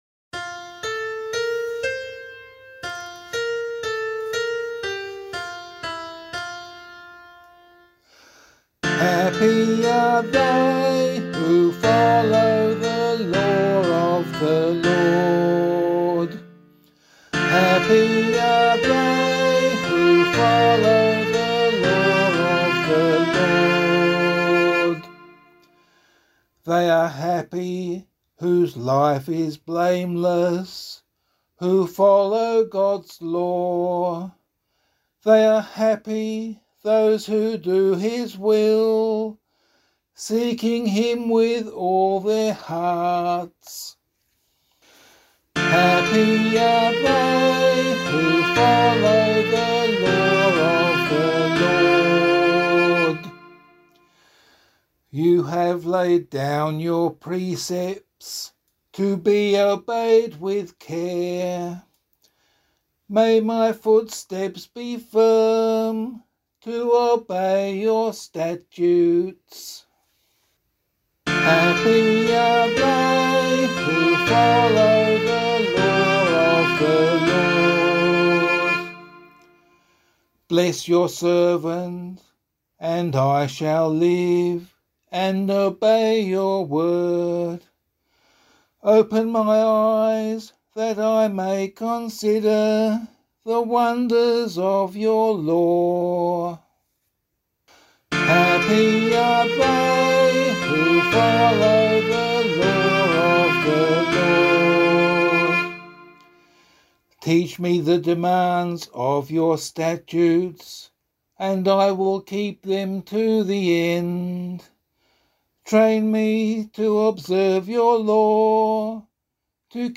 040 Ordinary Time 6 Psalm A [LiturgyShare 1 - Oz] - vocal.mp3